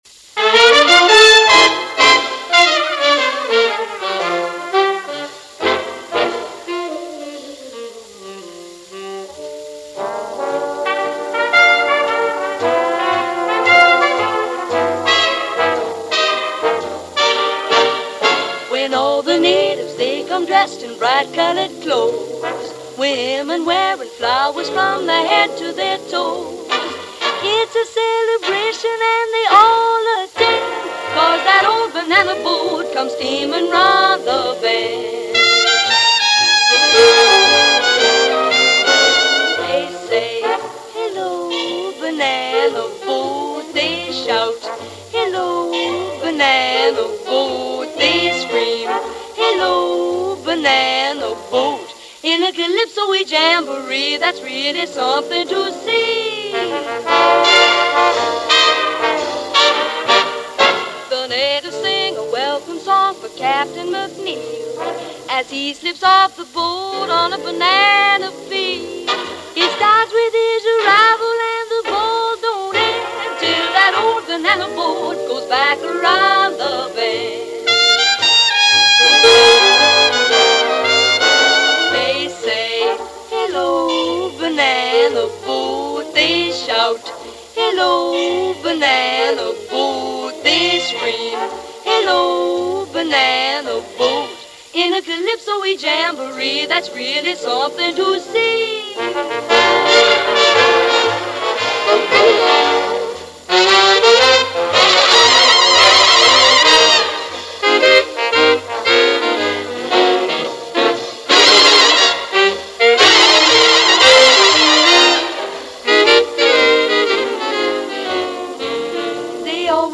Big Band era